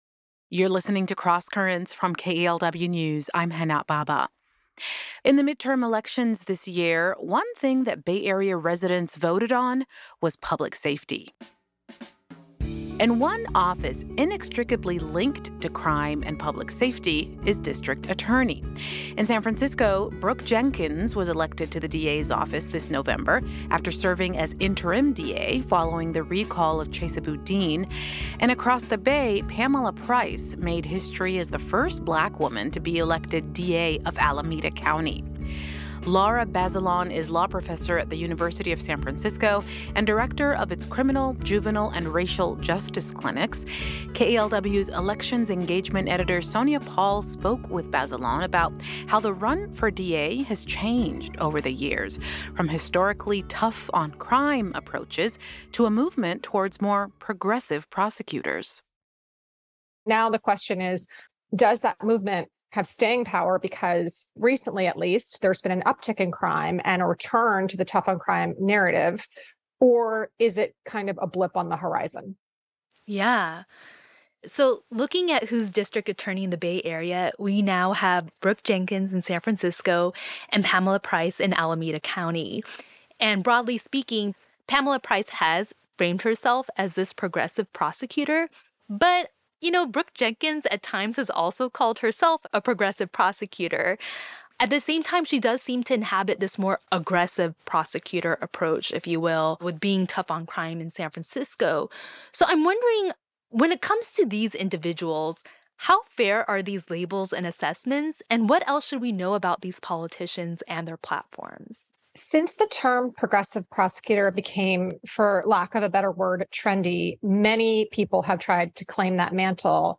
The following piece is one of the post-elections interviews I conducted about how public safety influenced the elections.